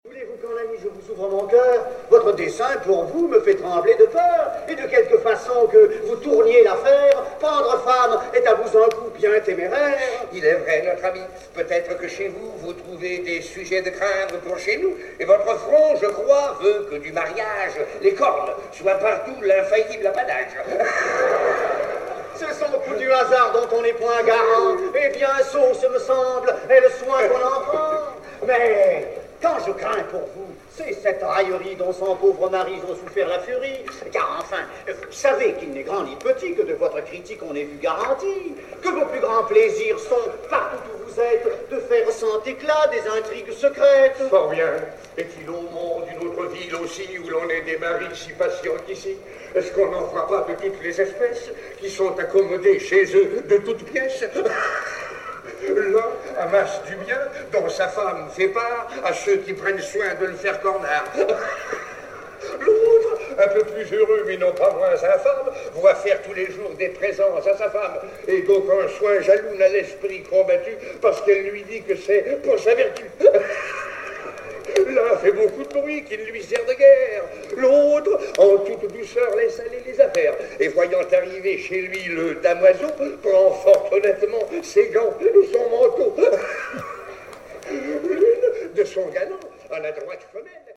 Le premier par un «scandeur», le deuxième par l'inimitable Louis Jouvet.